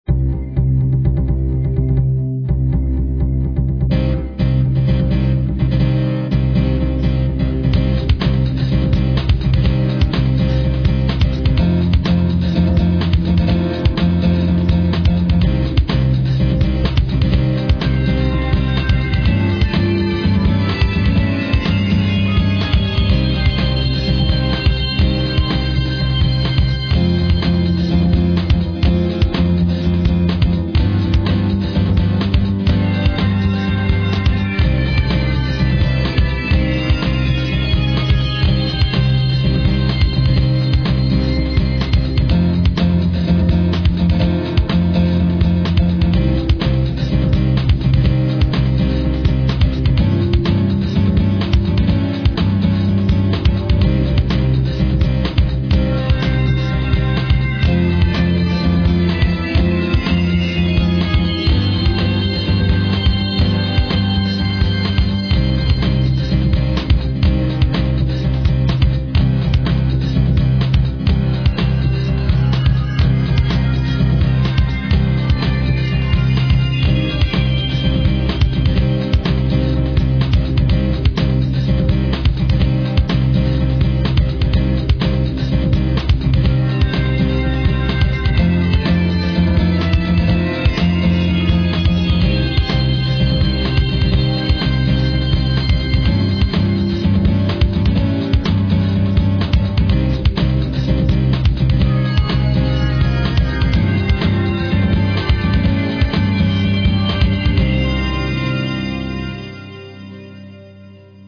Royalty Free Music for use in any type of